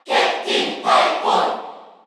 Captain_Falcon_Cheer_Korean_SSBU.ogg